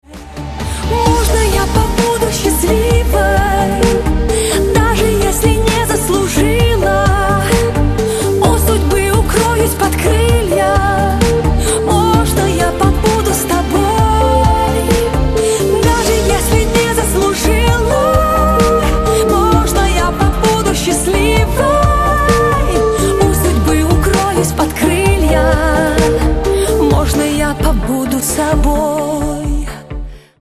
• Качество: 128, Stereo
поп
женский вокал
спокойные
лиричные
Отрывок песни известной российской эстрадной певицы.